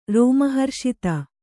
♪ rōma harṣita